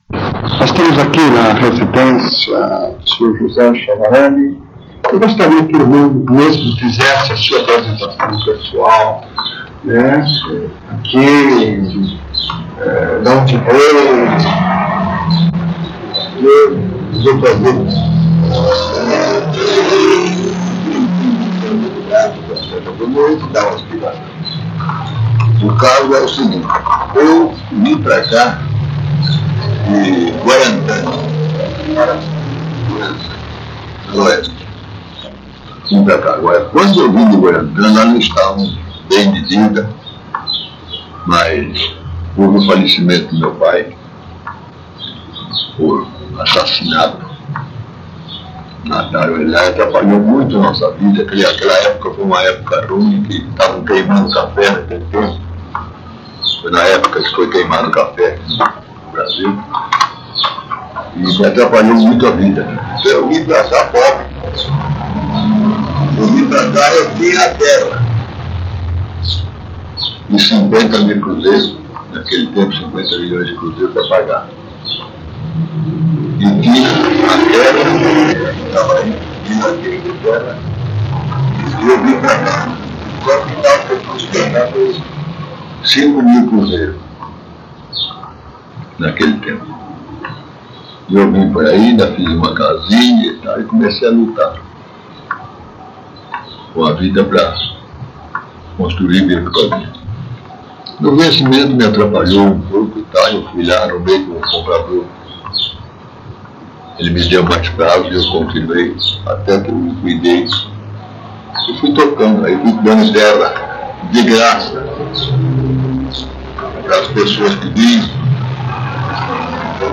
Entrevista
*Recomendado ouvir utilizando fones de ouvido.